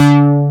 MKSBASS4.wav